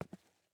Player Character SFX / Footsteps